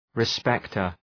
Προφορά
{rı’spektər}